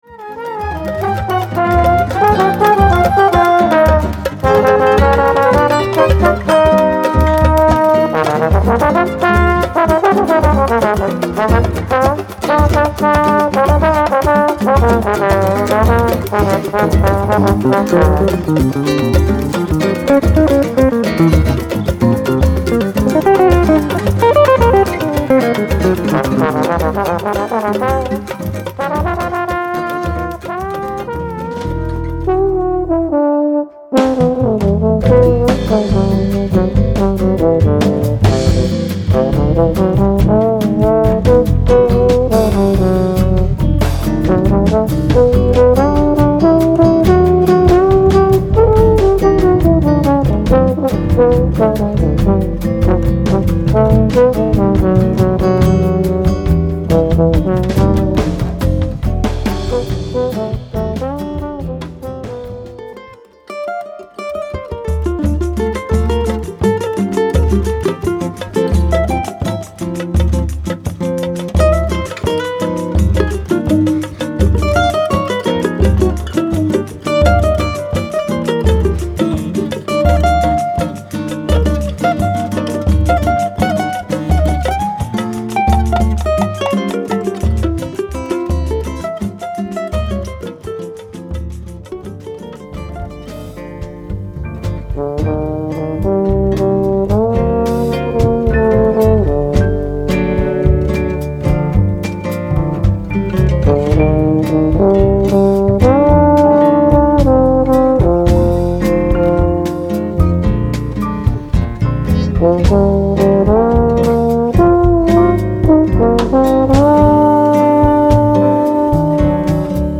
Brésil / Choro / Capoeira
clarinette
accordéon
pandeiro, tambourin
congas, bongos, cloche- 2015